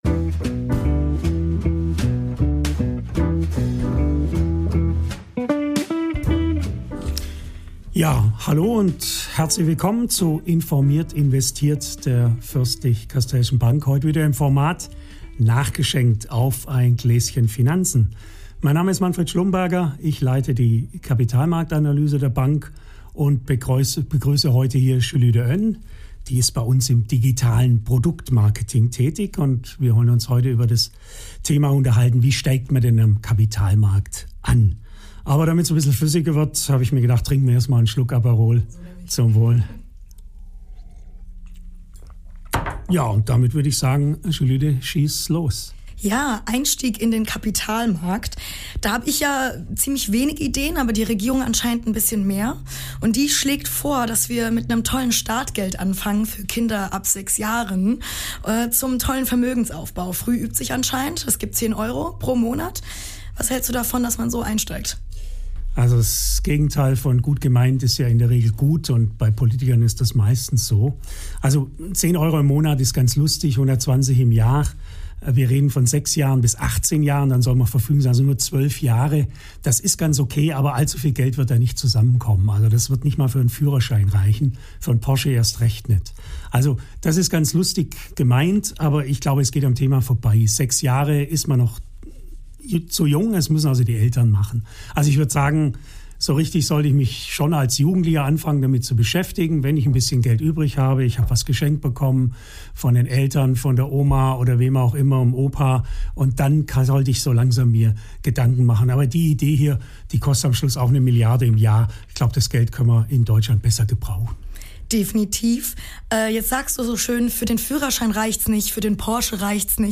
Ehrlich, humorvoll und natürlich mit einem Gläschen Aperol.